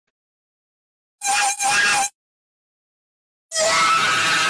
distorted screaming Meme Sound Effect
distorted screaming.mp3